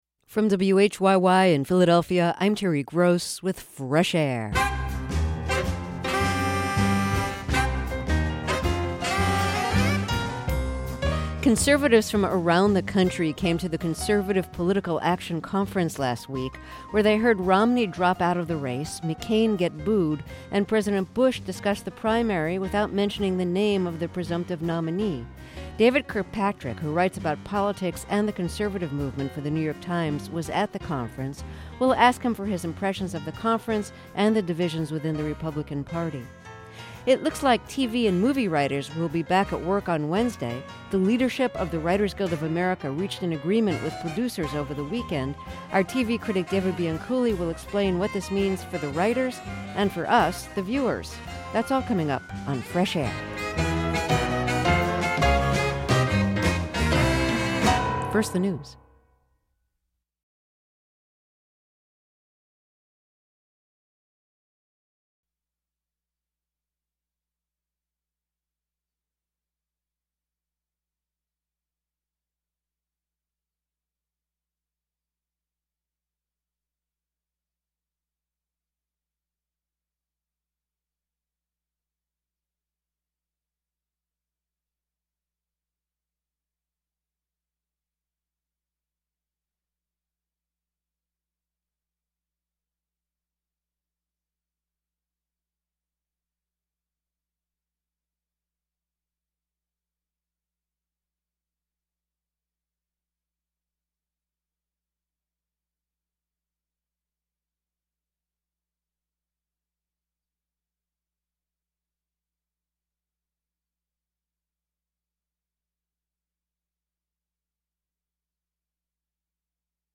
Fresh Air's TV critic David Bianculli discusses the long-term effects of the four-month-long writers' strike, and--more immediately--when we can expect new episodes of our favorite shows to return to the air.